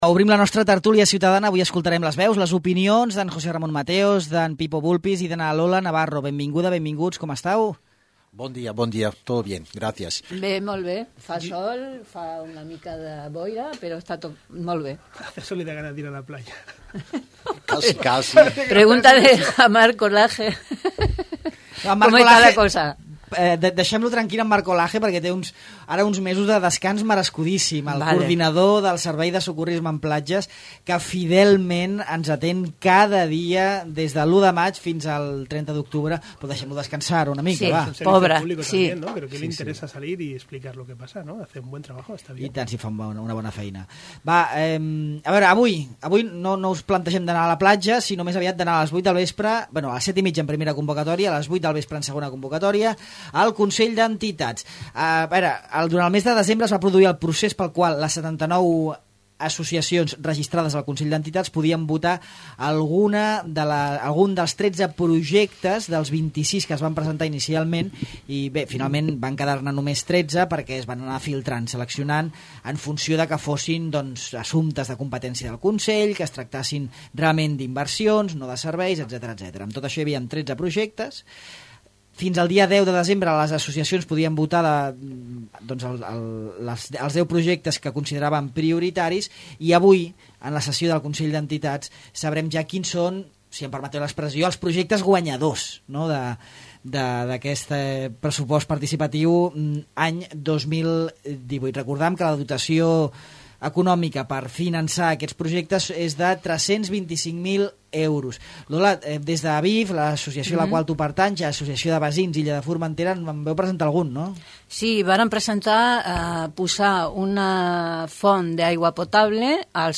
La tertúlia ciutadana parla sobre els atacs de cans, Sa Unió i el Consell d'Entitats